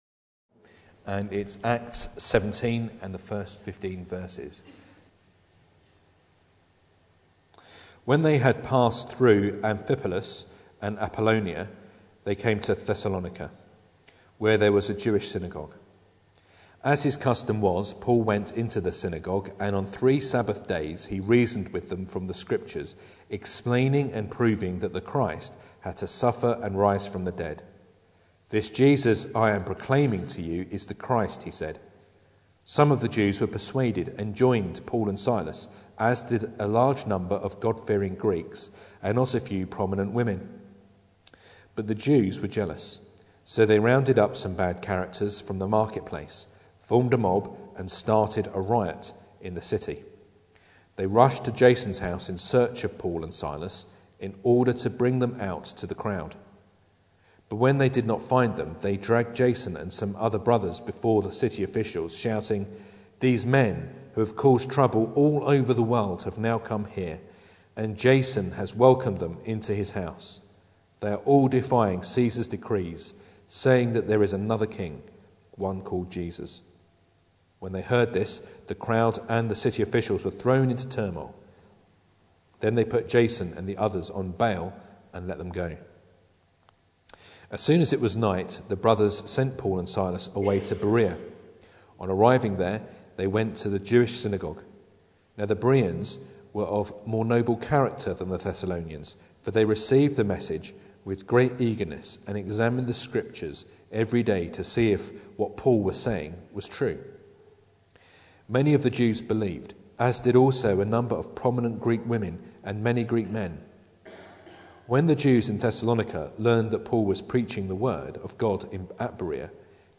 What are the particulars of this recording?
Acts 17:1-15 Service Type: Sunday Evening Bible Text